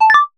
notification_sounds
friendly.ogg